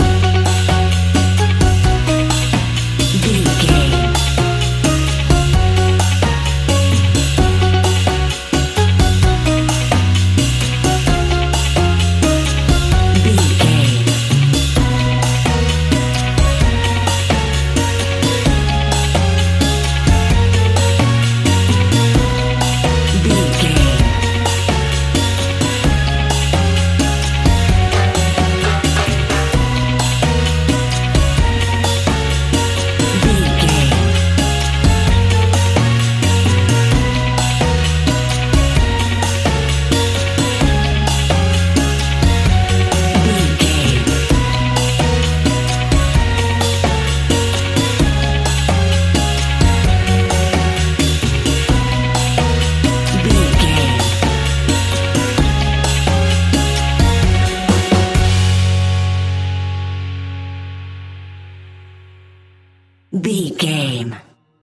Uplifting
Ionian/Major
F#
steelpan
calypso music
drums
percussion
bass
brass
guitar